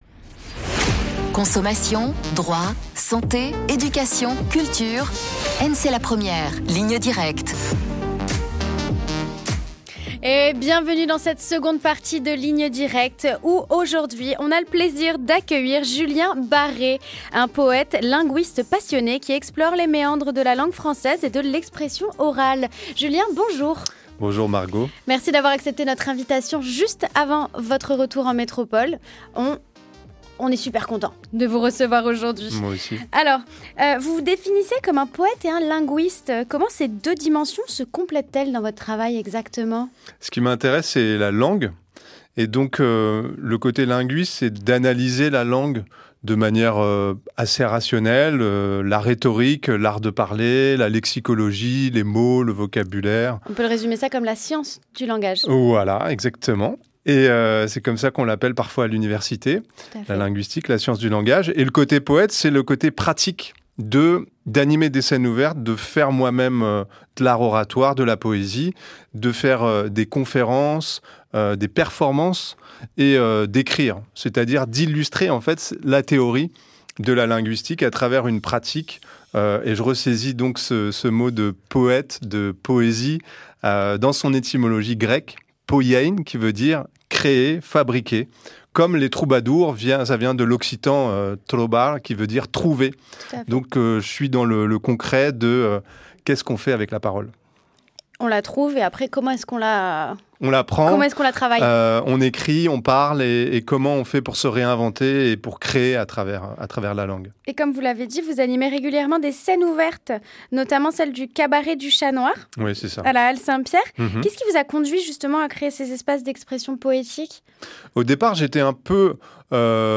Invité en direct à la radio Outre mer la 1ère
C’était dans les studios de radio Outre mer la 1ère en Nouvelle-Calédonie, après douze jours de voyage sur le « caillou ».